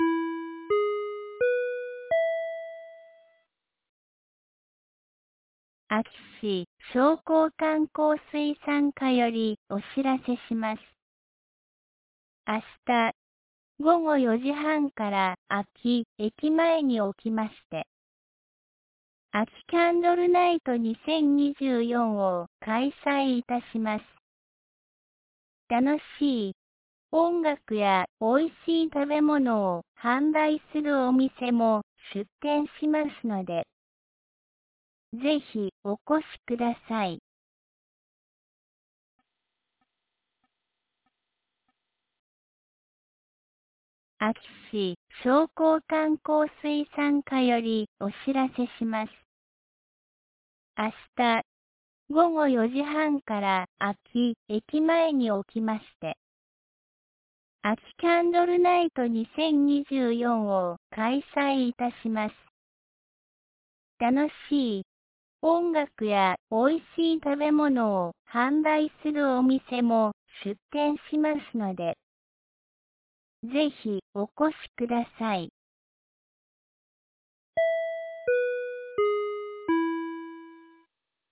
2024年12月13日 12時41分に、安芸市より全地区へ放送がありました。